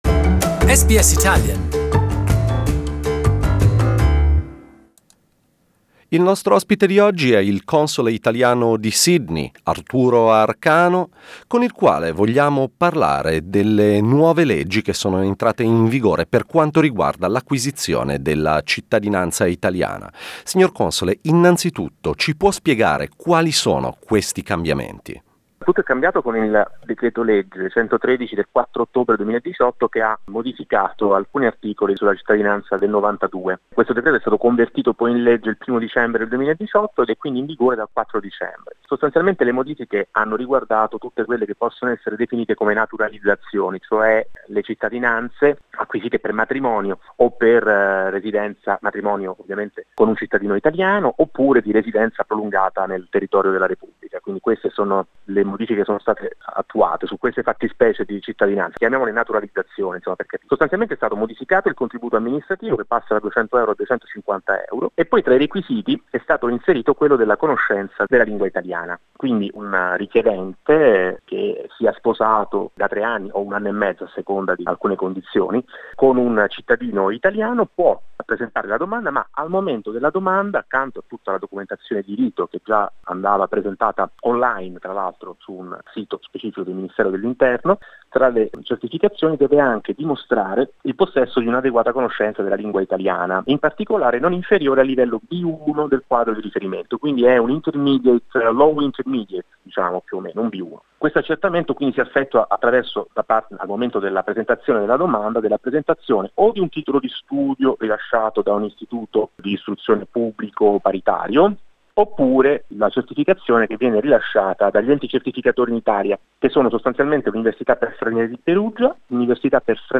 Italian Consul of Sydney, Arturo Arcano explained during our program how the new laws apply and the way they will affect future applications for citizenship.